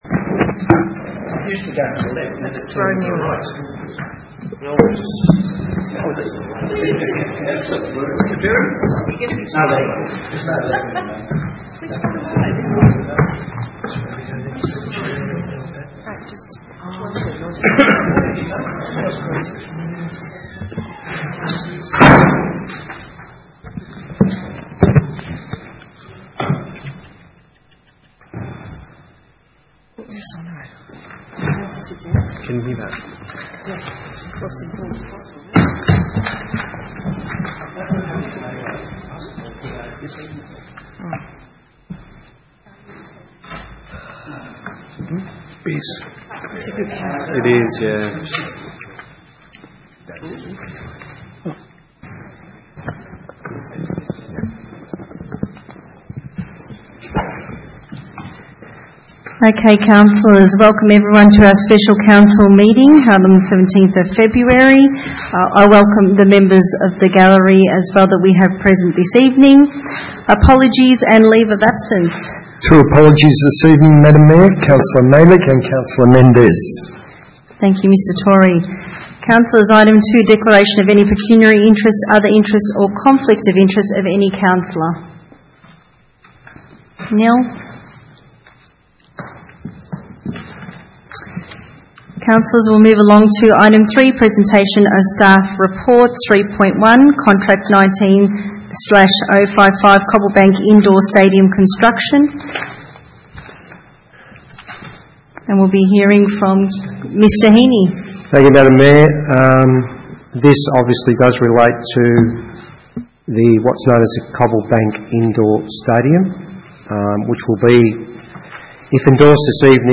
Special Meeting 17 February 2020
Council Chambers, 232 High Street, Melton, 3337 View Map